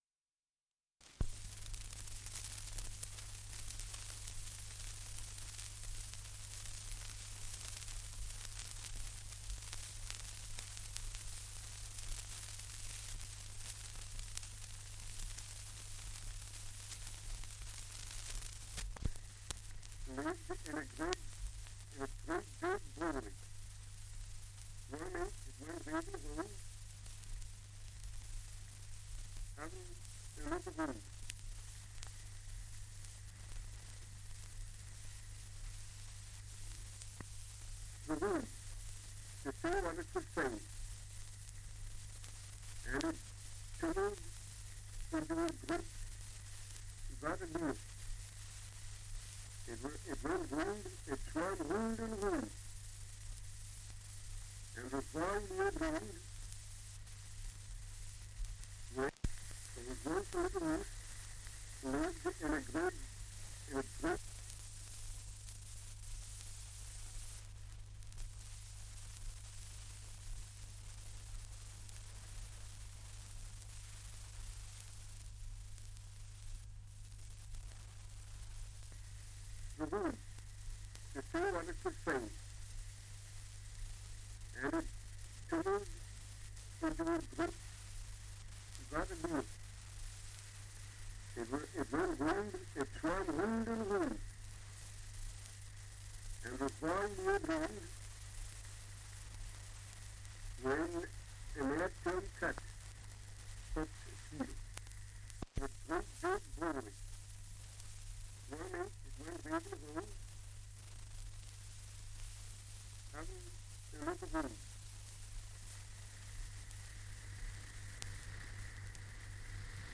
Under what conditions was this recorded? Dialect recording in Belford, Northumberland 78 r.p.m., cellulose nitrate on aluminium